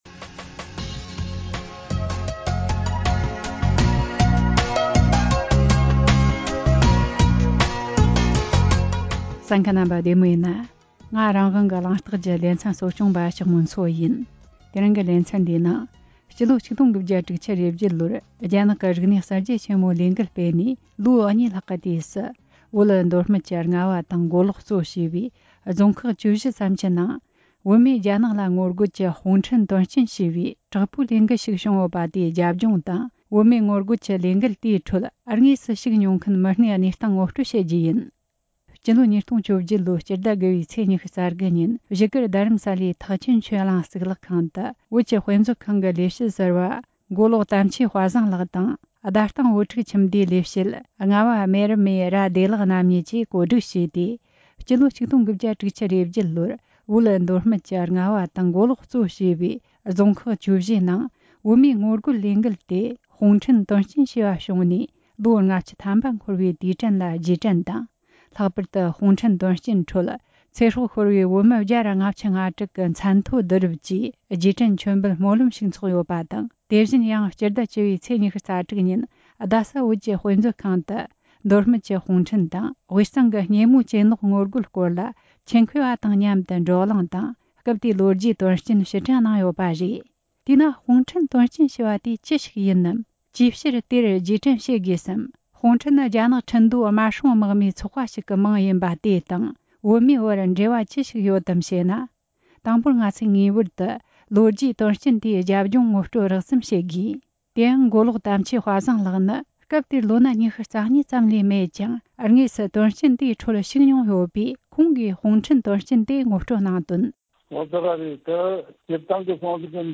རྒྱ་ནག་གི་རིག་གནས་གསར་བརྗེའི་སྐབས་བོད་མིས་རྒྱ་ནག་ལ་ངོ་རྒོལ་གྱེན་ལངས་ཀྱི་ལོ་རྒྱུས་དོན་རྐྱེན་ཐད་འབྲེལ་ཡོད་མི་སྣ་ལ་བཀའ་འདྲི་ཞུས་པ།